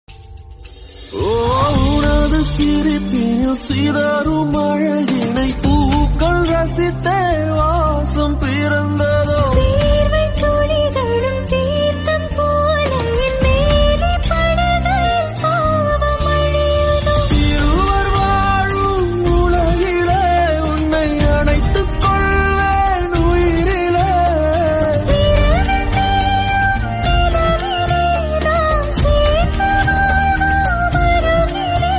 soulful tune